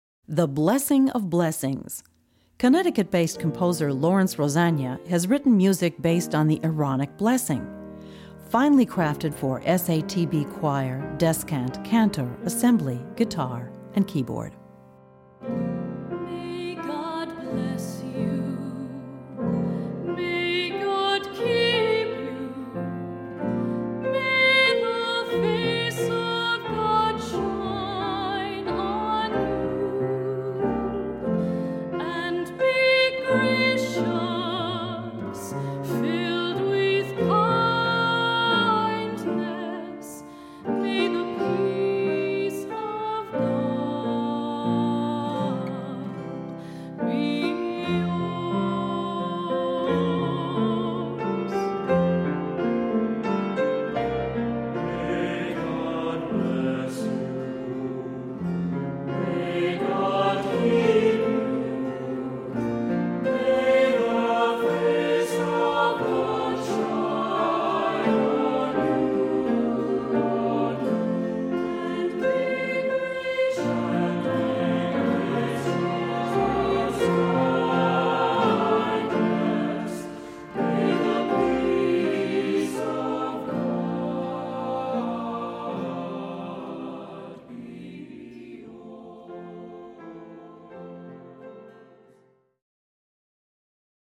Voicing: Cantor, assembly, descant,SATB